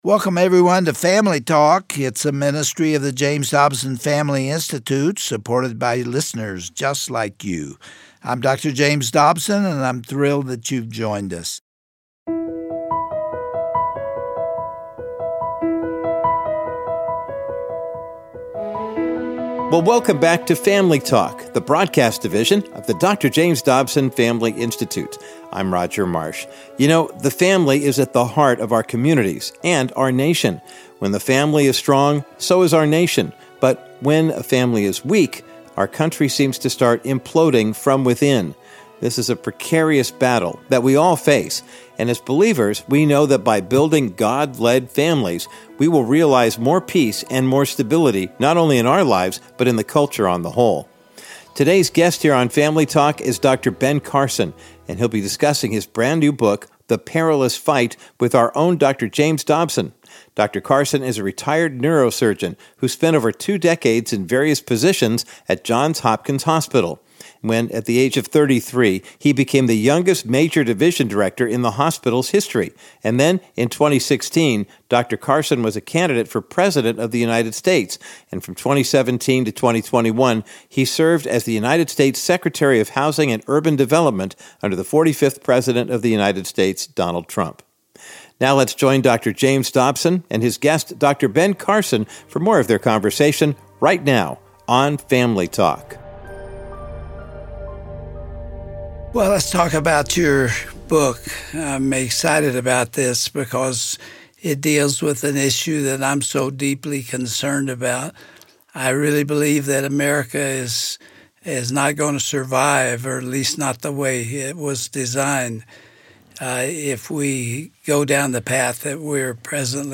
On today’s edition of Family Talk, Dr. James Dobson concludes his honest and open discussion with Dr. Ben Carson. They discuss his book, The Perilous Fight: Overcoming Our Culture’s War on the American Family, and his great hope for America, even in the midst of these immoral and destructive times.